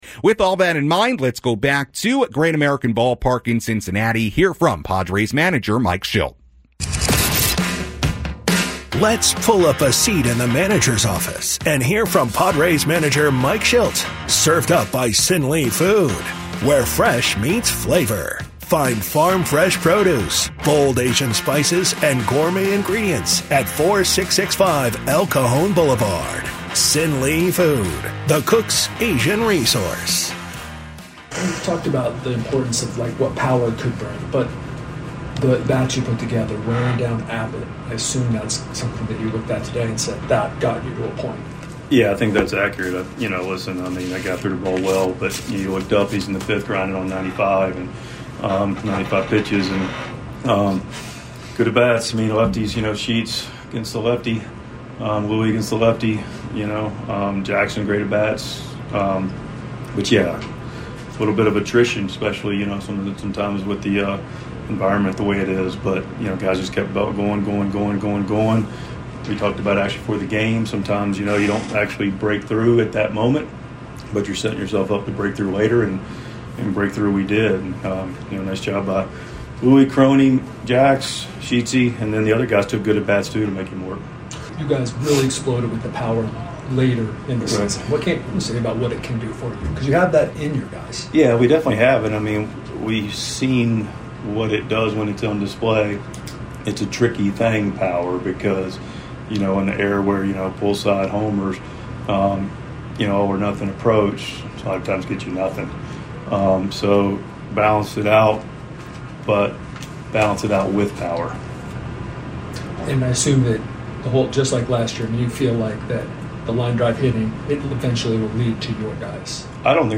6.28.25 Mike Shildt Postgame Press Conference (Padres 6, Reds 4)